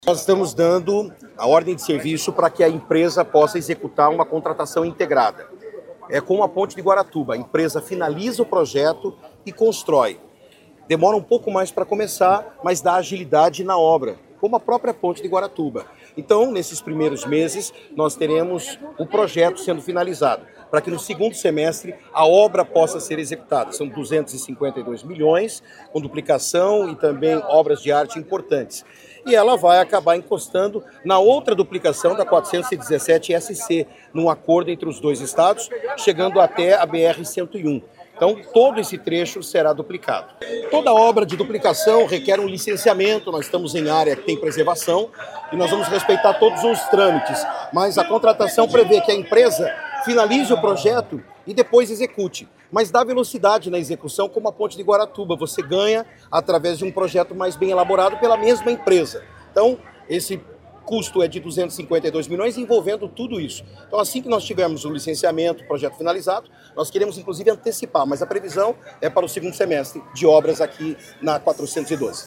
Sonora do secretário Estadual da Infraestrutura e Logística, Sandro Alex, sobre a autorização da duplicação da PR-412, em Guaratuba